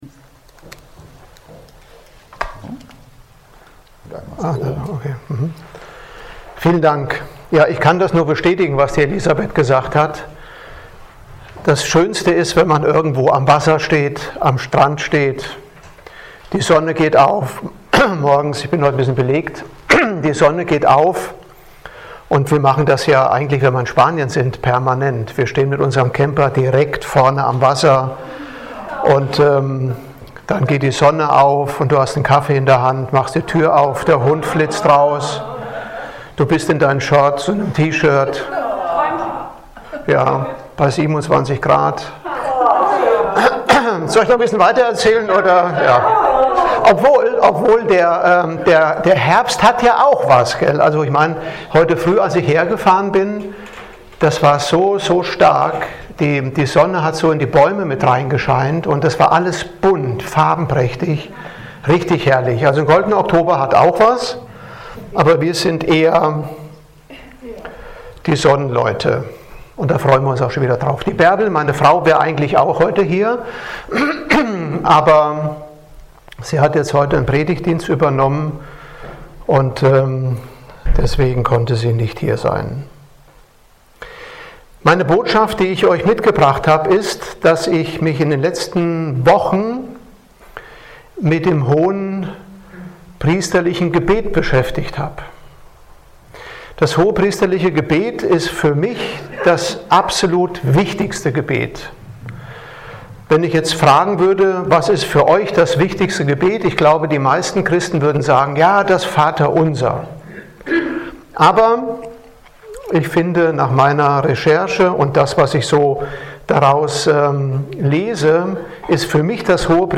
Externe Prediger